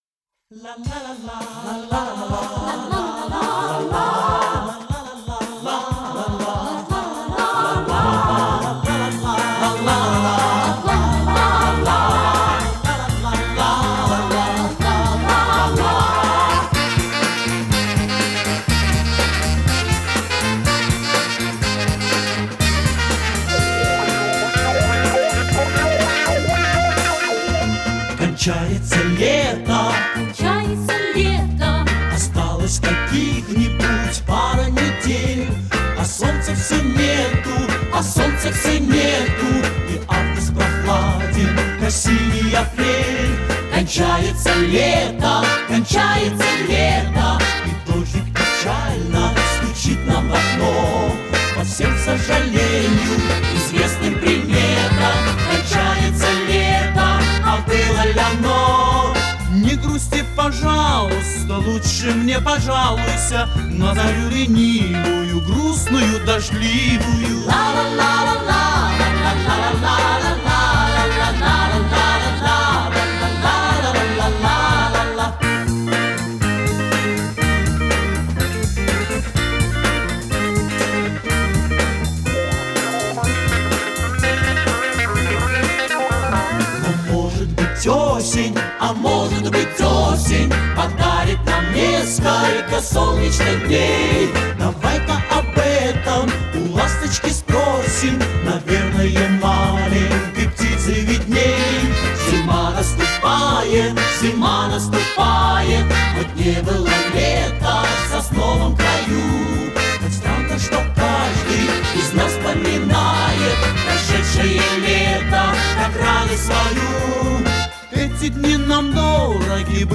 (компакт-кассета)